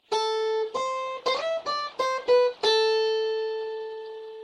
描述：重建吉他声。